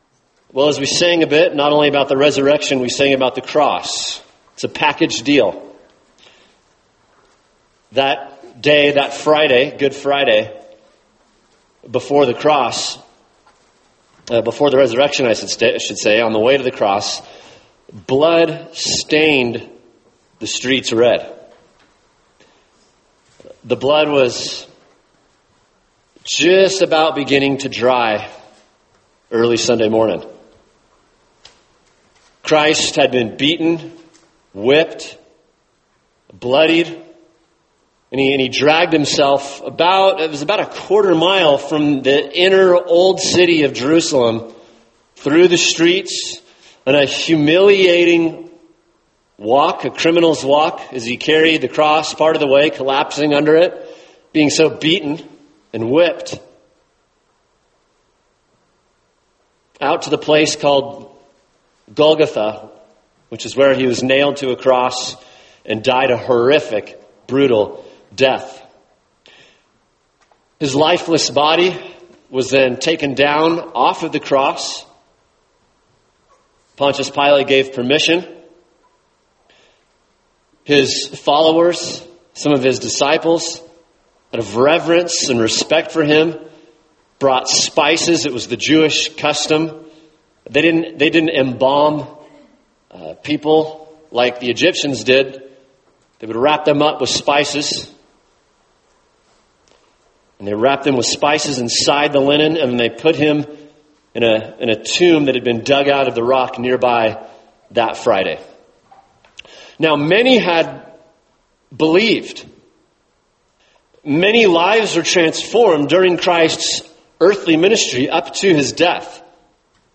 [sermon] Romans 4:25 – The Reason for the Resurrection | Cornerstone Church - Jackson Hole